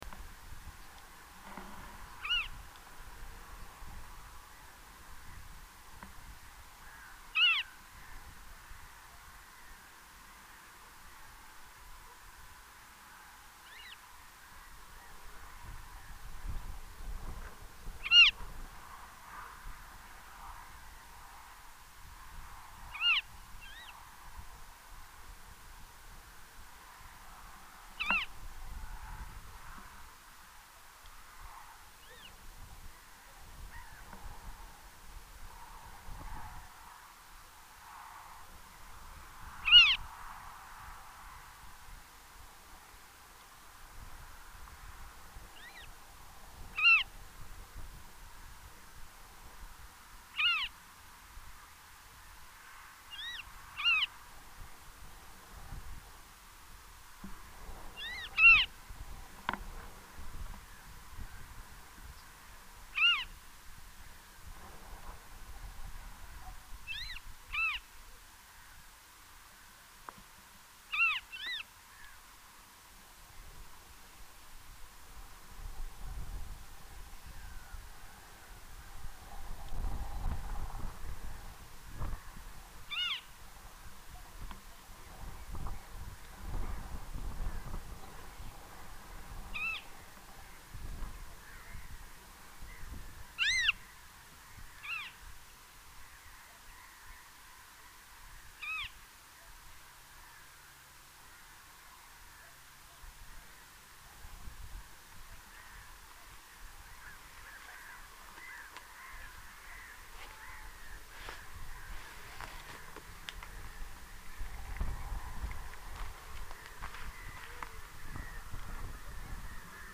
Falco di palude
(Circus aeruginosus)
Falco-di-palude-Circus-aeruginosus.mp3